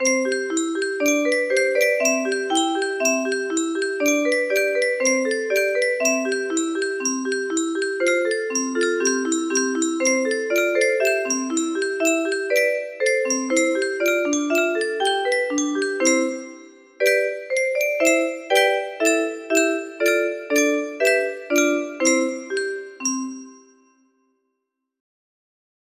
Clone of お正月 - Oshōgatsu - Japanese New Year Song - Rentarō Taki music box melody